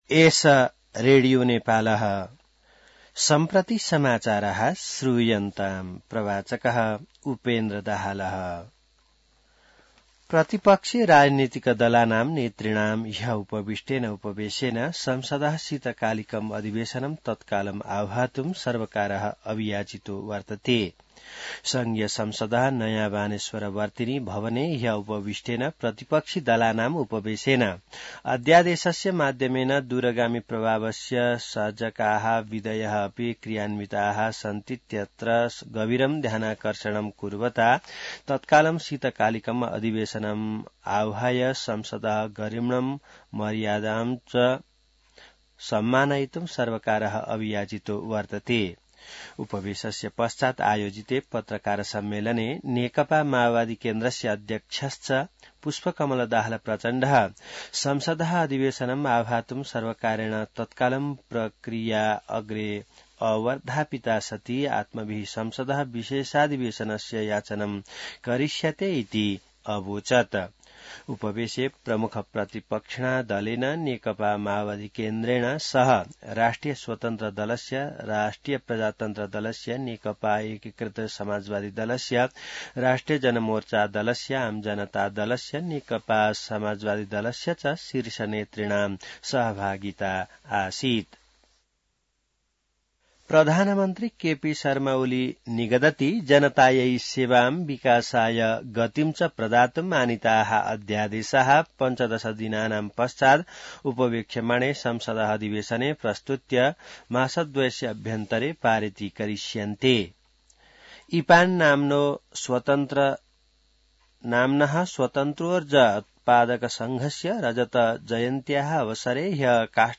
An online outlet of Nepal's national radio broadcaster
संस्कृत समाचार : ६ माघ , २०८१